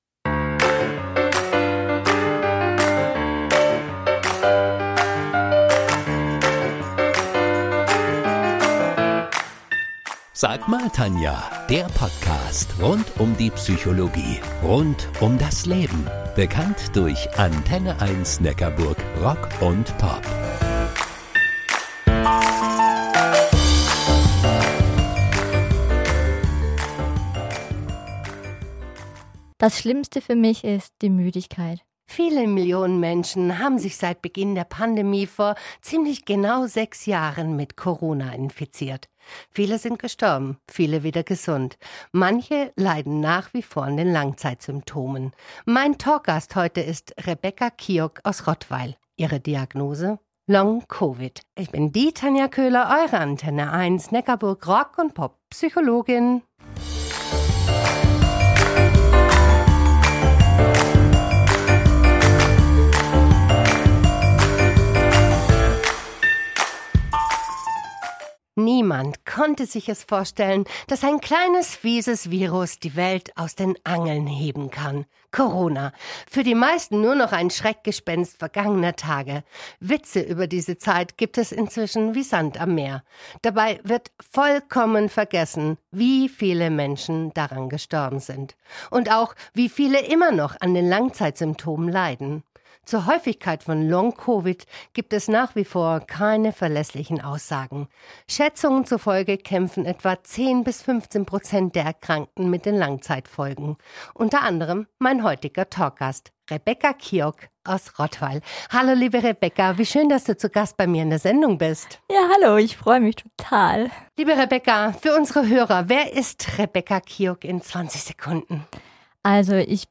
Im Gespräch
Diese Podcast-Episode ist ein Mitschnitt der Original-Redebeiträge